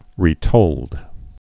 (rē-tōld)